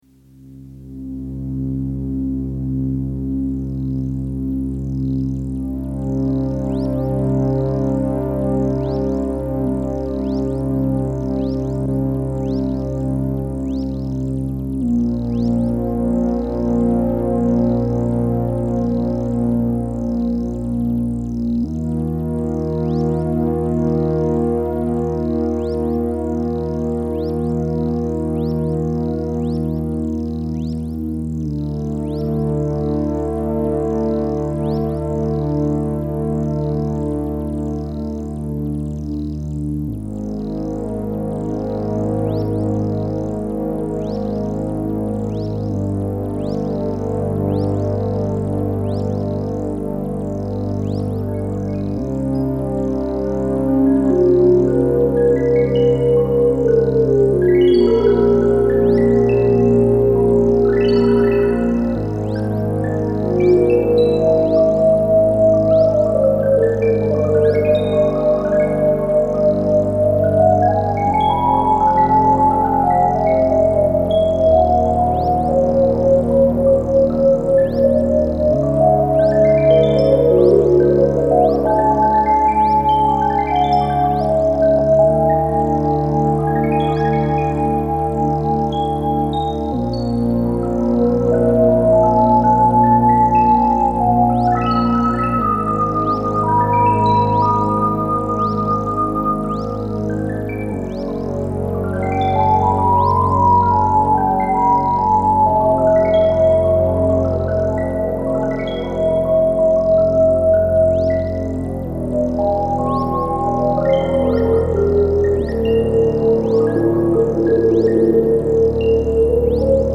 "Flute" Improvisation 1985 A pitch track and the pitch-to-cv interface keeps things together without the need for click tracks or scores.
This allows multiple tracks to be built up that are harmonically related to each other.
FluteImprov.mp3